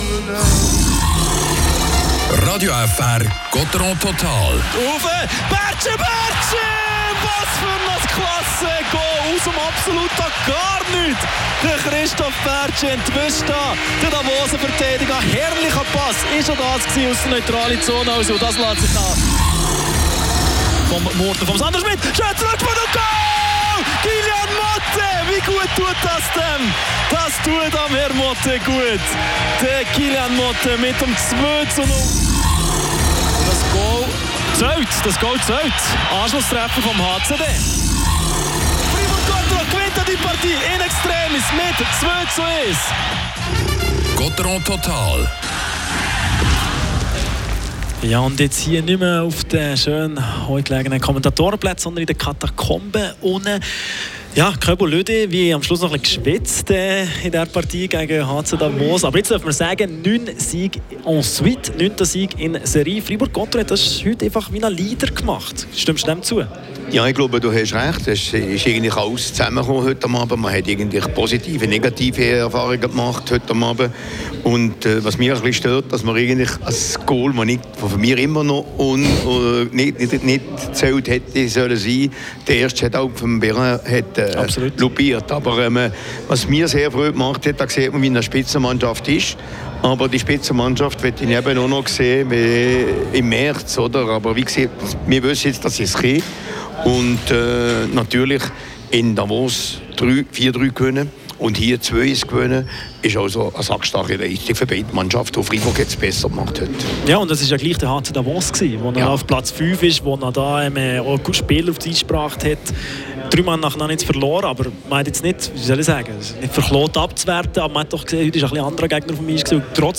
Spielanalyse von
Interview